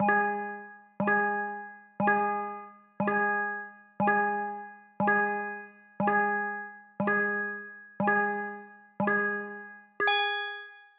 count_10.wav